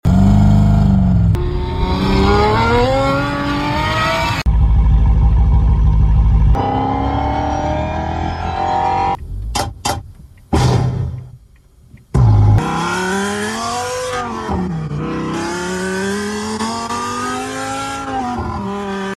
Over 100+ Exhaust Sounds | Sound Effects Free Download